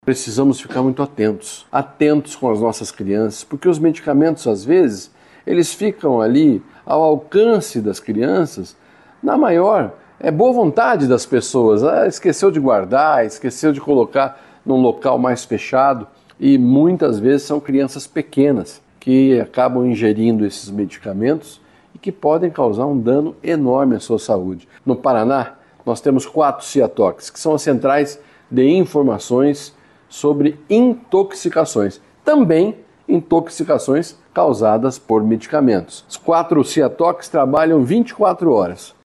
O secretário estadual da Saúde, Beto Preto, explica que os casos costumam acontecer quando medicamentos se encontram em fácil acesso das crianças.